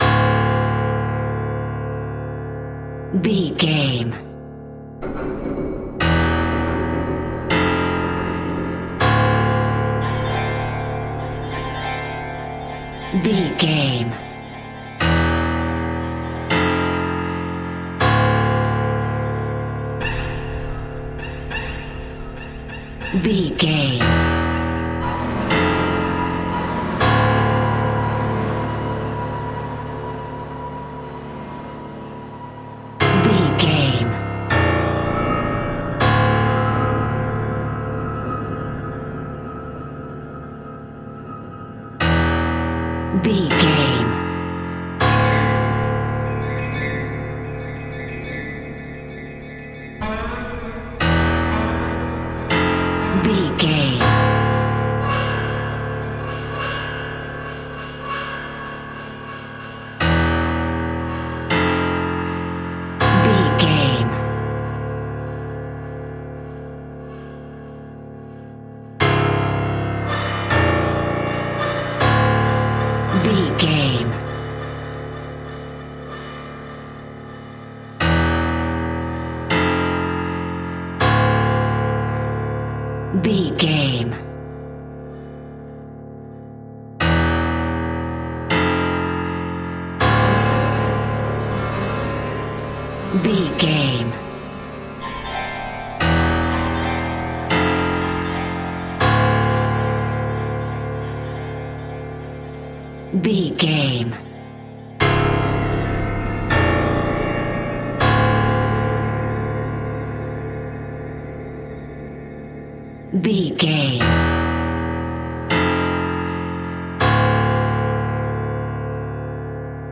Prepared Piano Ambience.
Aeolian/Minor
D
Slow
scary
tension
ominous
dark
suspense
haunting
eerie
creepy
synth
pads